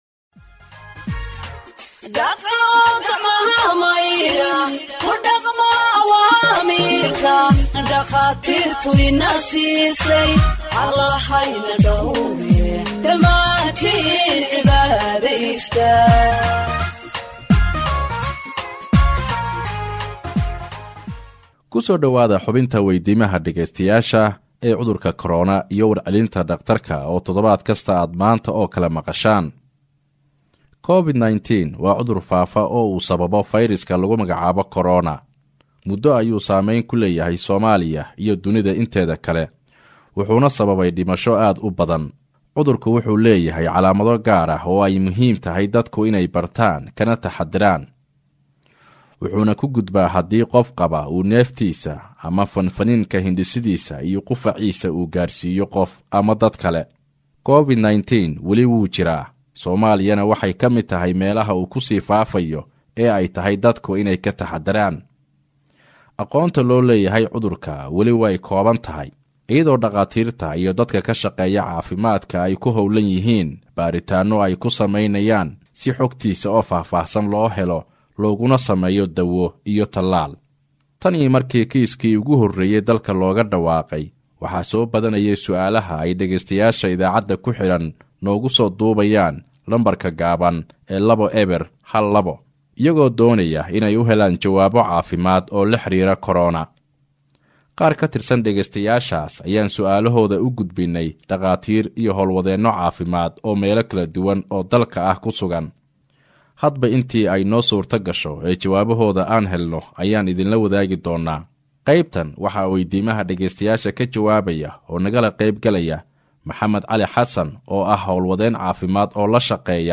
HEALTH EXPERT ANSWERS LISTENERS’ QUESTIONS ON COVID 19 (44)